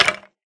skeletonhurt4.ogg